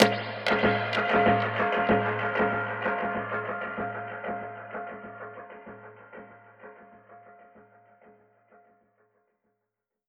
DPFX_PercHit_D_95-01.wav